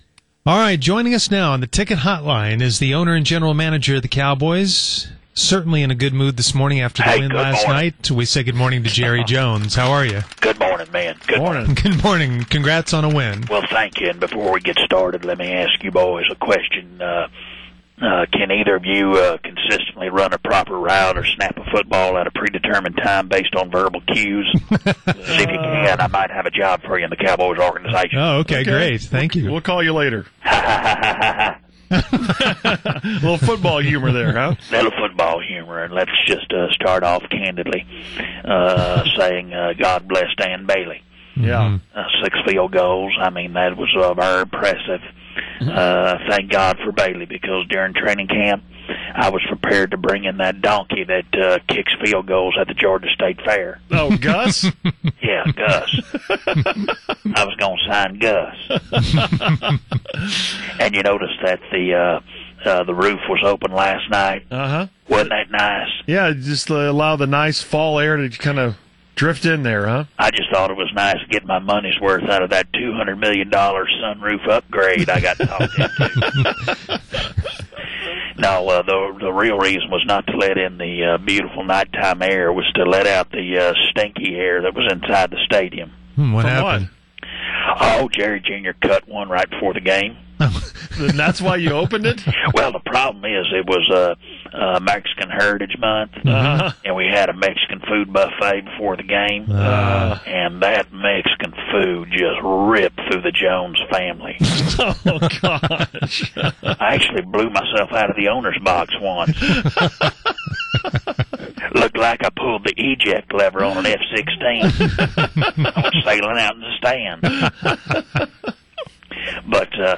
Fake Jerry pops on with the Musers after the Dallas Cowboys beat the Washington Redskins on Monday night football.